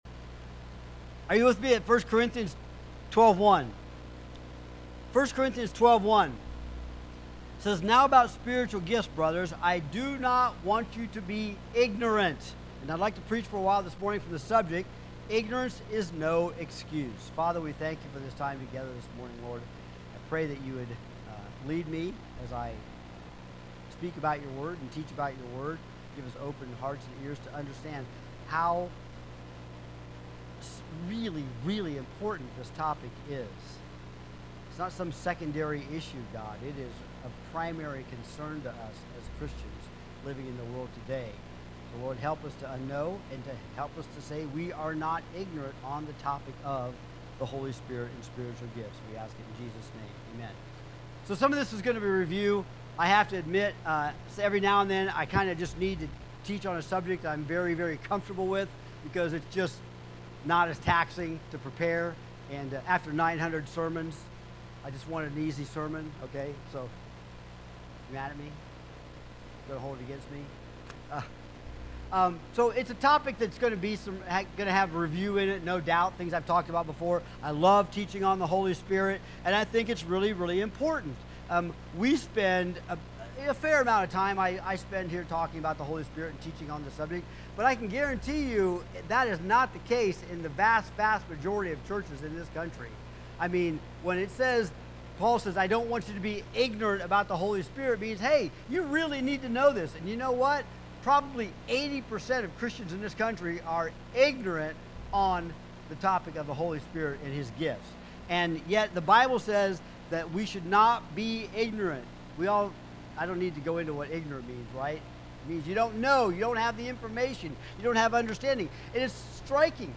Sermons | Ohio City Community Church of God